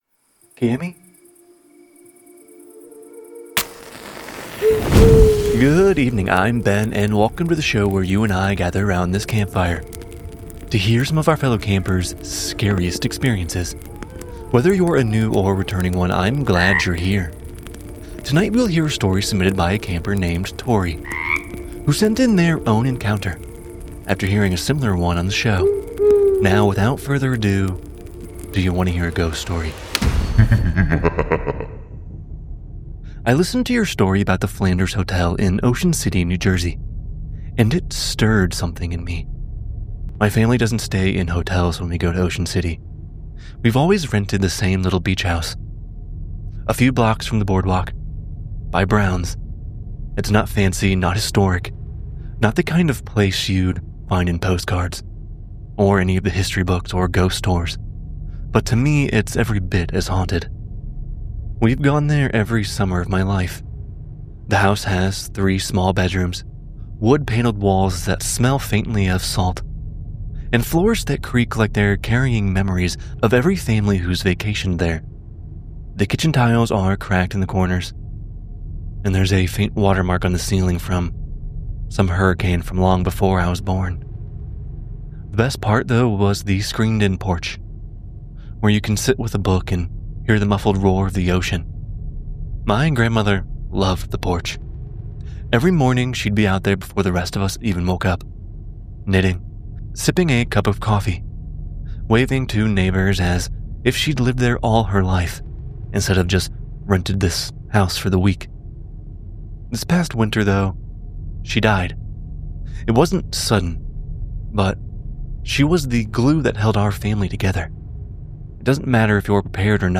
Narrated by:
Sound Design by: